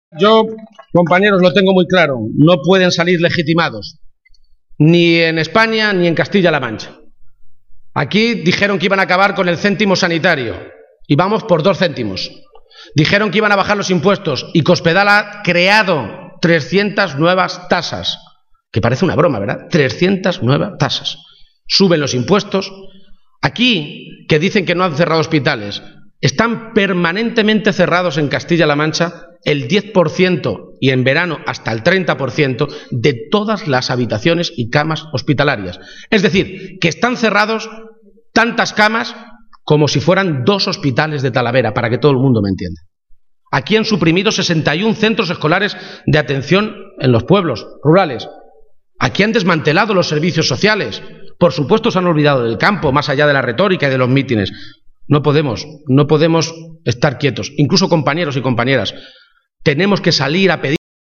En ese acto público, con el Teatro Victoria abarrotado, Page ha dado toda una serie de argumentos por los que asegurar que una victoria del PSOE sobre el PP el domingo es importante.